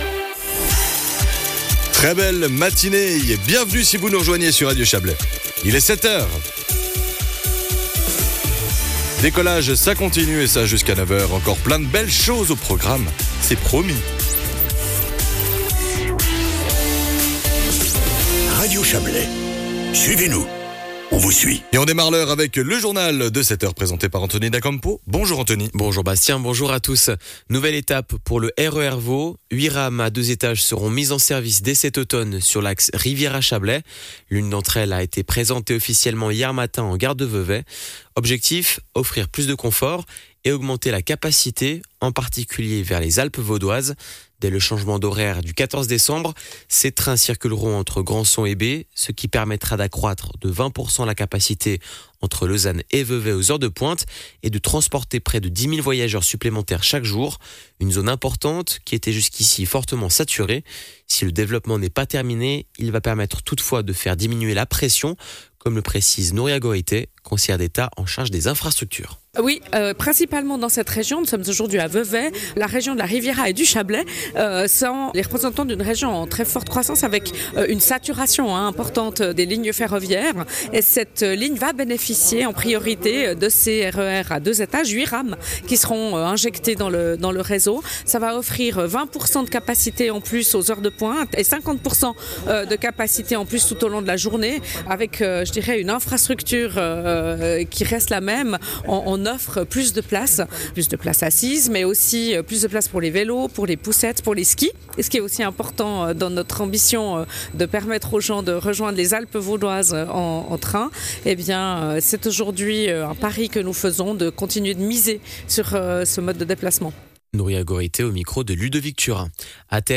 Le journal de 7h00 du 26.09.2025